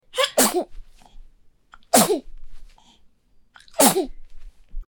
Nada dering Bayi Bersin
nada-dering-bayi-bersin-id-www_tiengdong_com.mp3